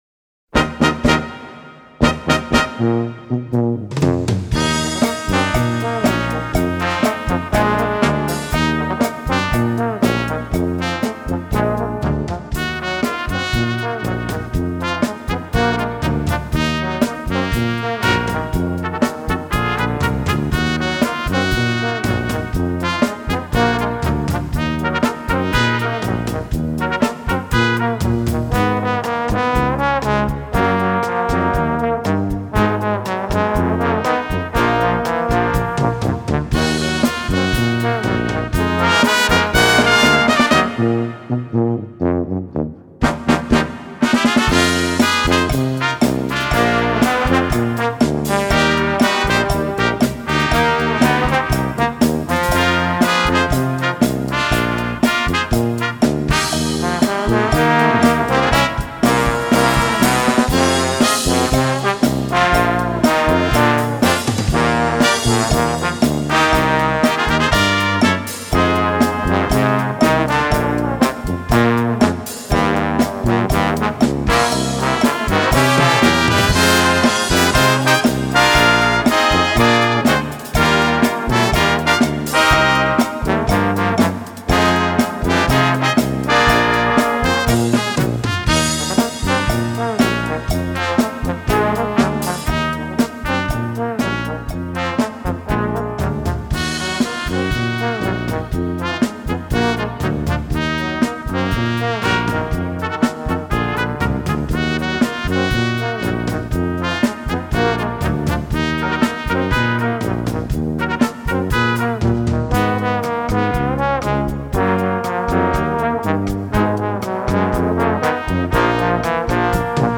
Gattung: Cha Cha für Blasorchester